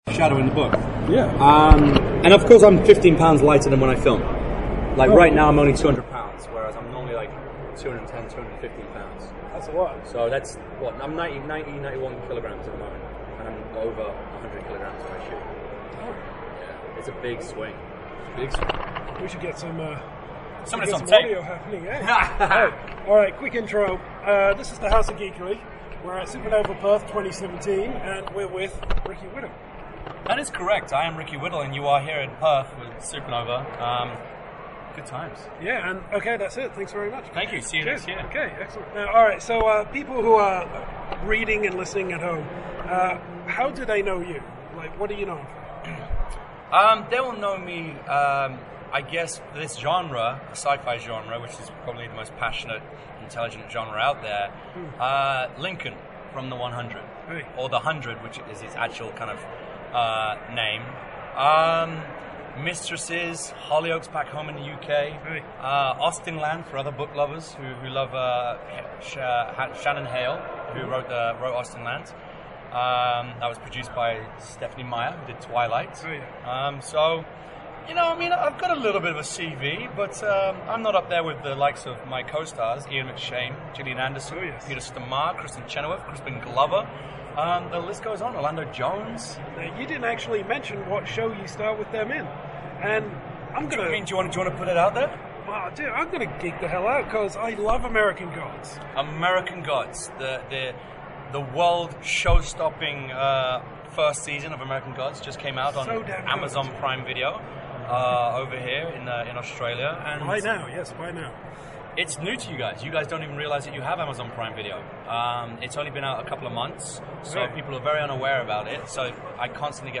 Exclusive Interview with Ricky Whittle!
This past weekend we were lucky enough to chat with Ricky Whittle at the Supanova convention. While he often appears as the strong, silent type on our TV screens Whittle is a ball of barely contained energy.